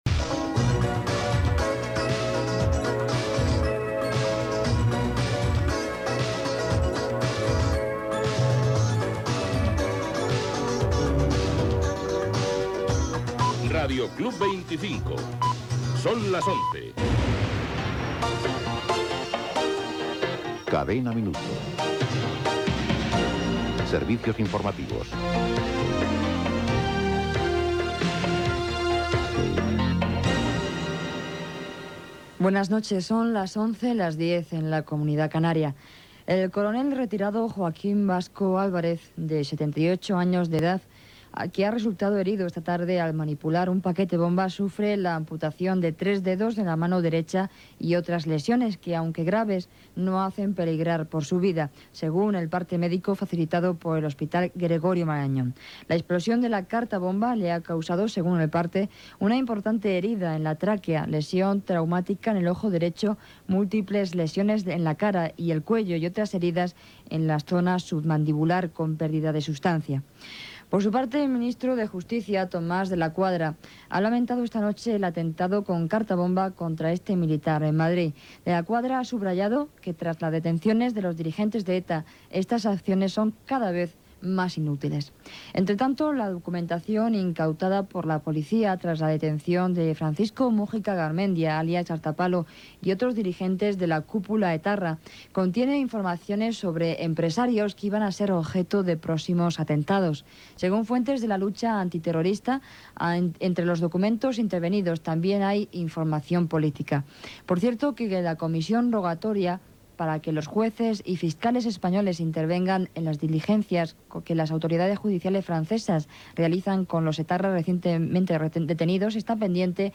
Indicatiu de l'emissora, hora, careta del programa, hora, militar ferit a Madrid en rebre una carta bomba enviada per ETA, embargament de Nacions Unides a Líbia. Indicatiu de l'emissora, hora, temperatura i tema musical
Informatiu
FM